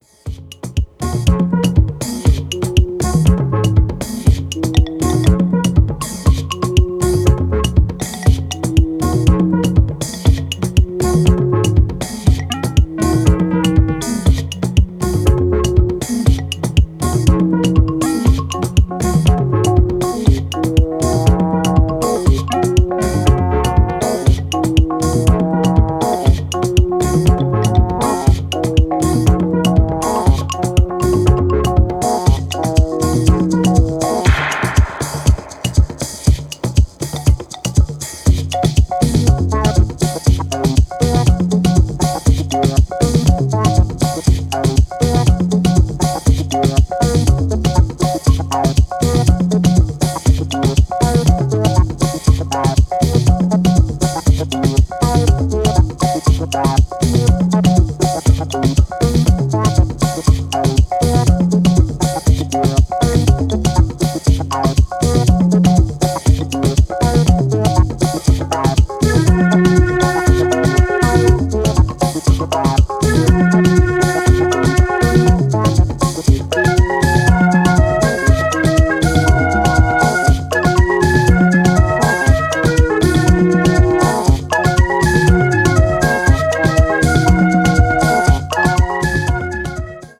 the psychedelic, beat-driven, tropical, house group